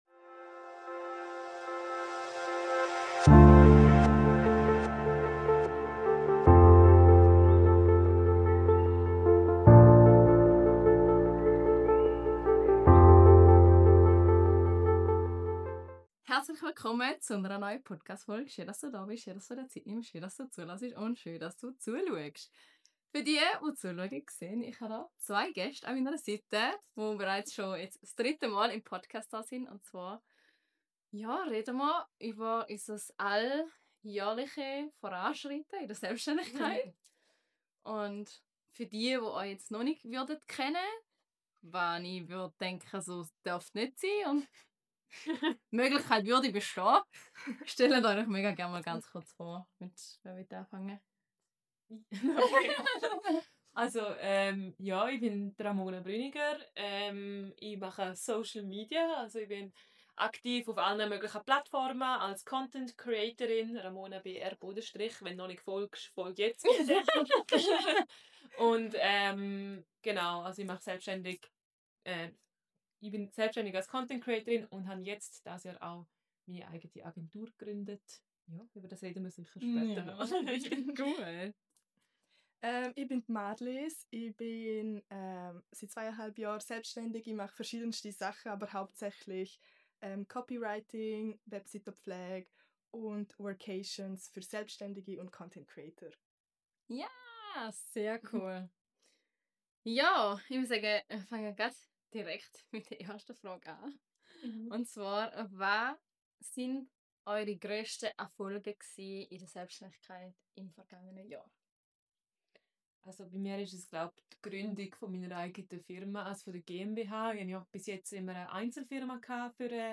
Drei Frauen.